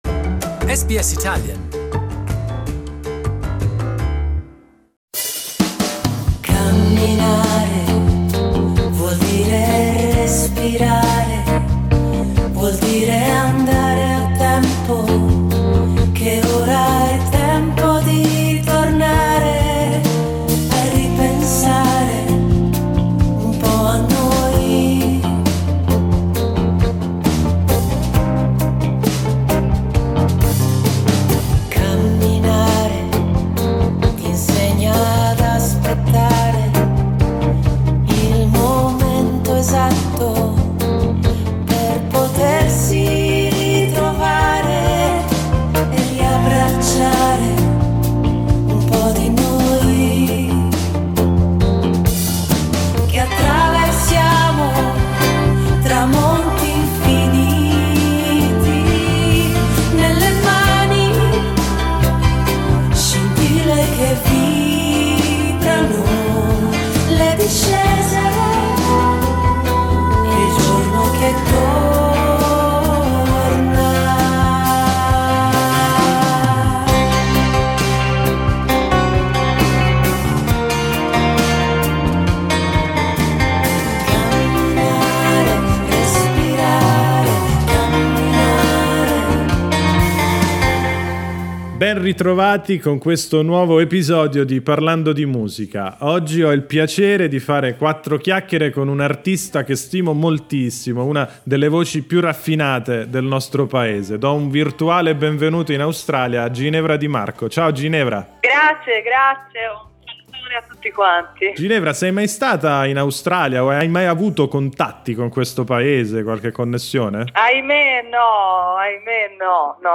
A conversation with Italian singer Ginevra Di Marco